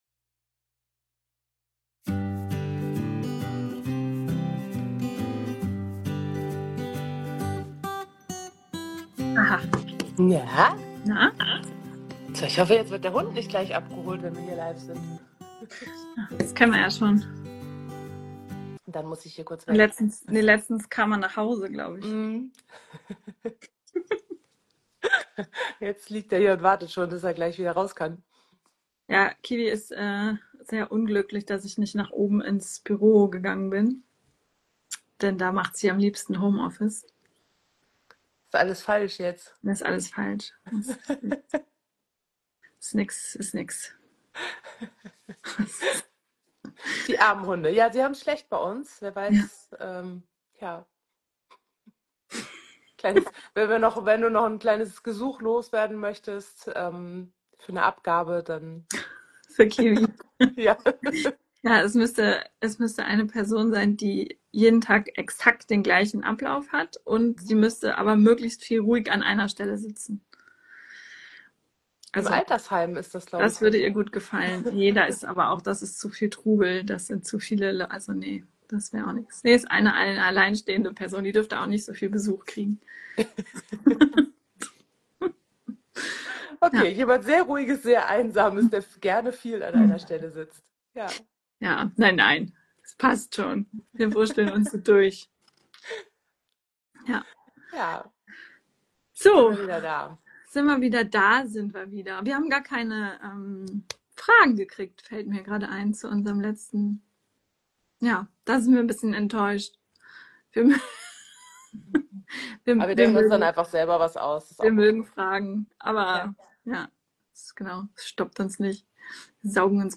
In unserer Instagram Live Reihe beschäftigen wir uns im Moment intensiv mit den Besonderheiten von Hibbelhunden in Deinem Training. In der zweiten Folge geht es um die Wichtigkeit des Umgangs im Alltag.